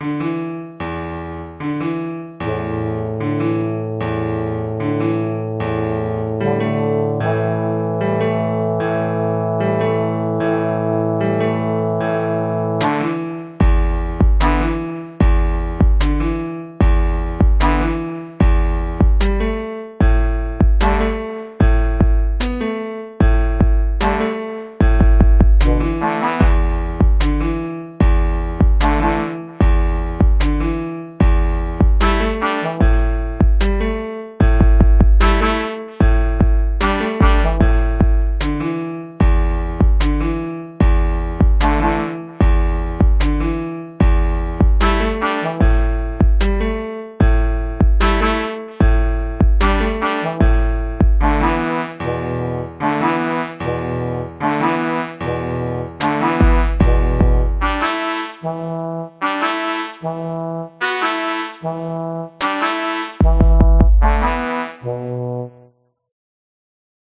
描述：一首短小的放克曲子，有小号、钢琴和节拍。
声道立体声